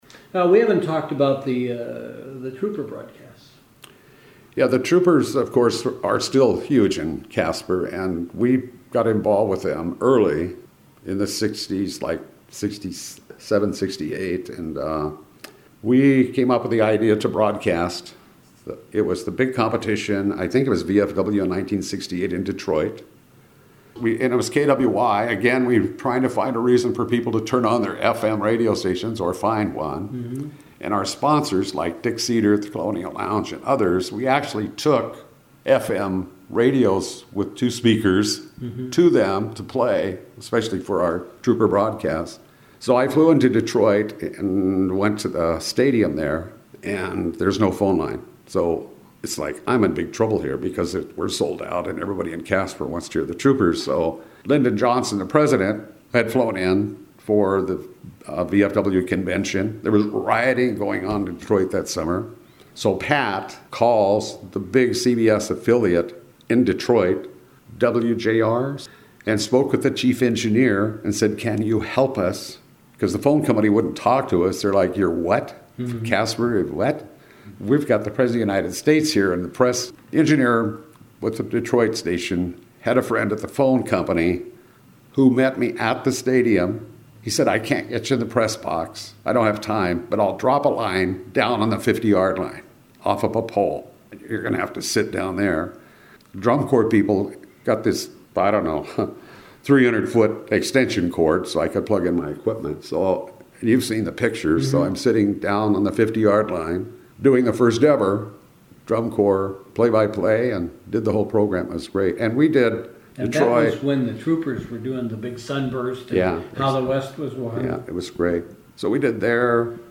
Trooper Broadcasts
in Kalispell, Montana